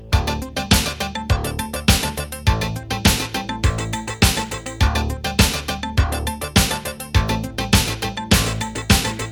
• Качество: 256, Stereo
без слов
Electronica
звонкие